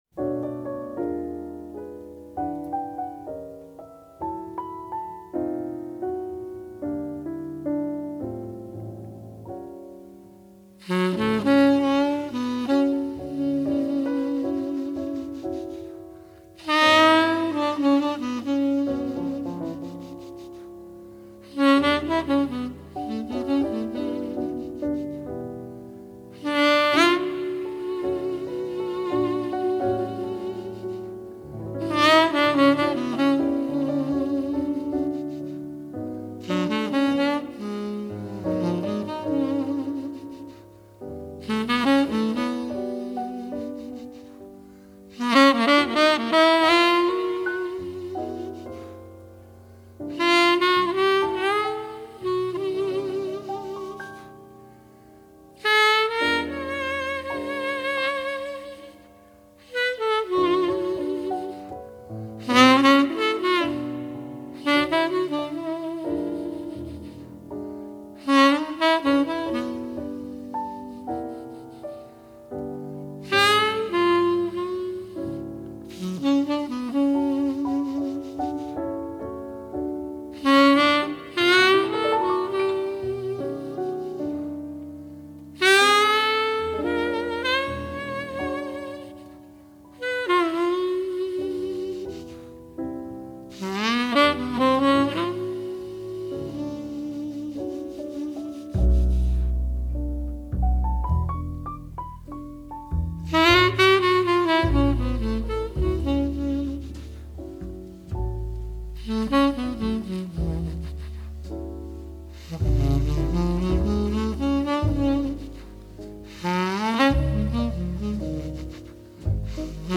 爵士及藍調 (544)
★ 如清流般柔和傾吐，撫慰人心的薩克斯風演奏！